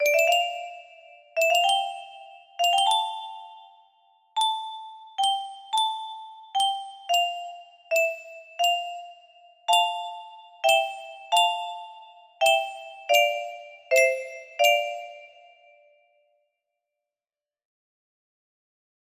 Kikkerland 15 music boxes More